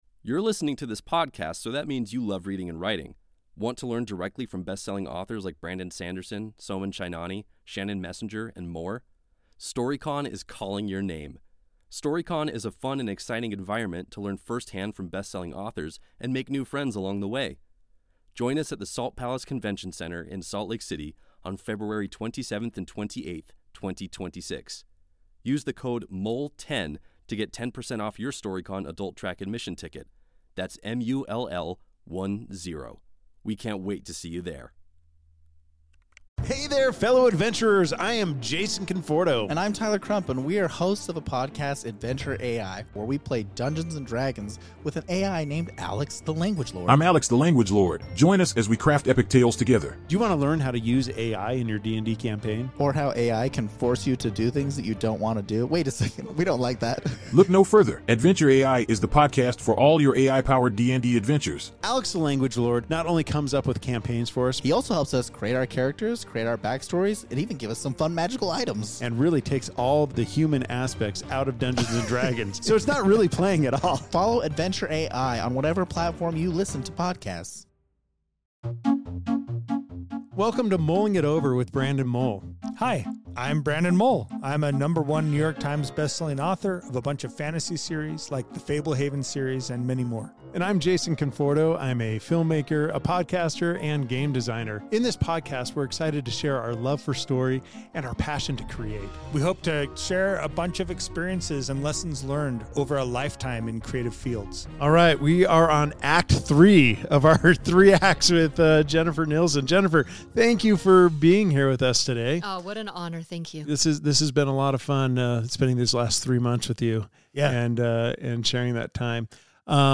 Be a guest on this podcast Language: en-us Genres: Arts , Books , Comedy , Comedy Interviews Contact email: Get it Feed URL: Get it iTunes ID: Get it Get all podcast data Listen Now... Editing & Advice with Jennifer A. Nielsen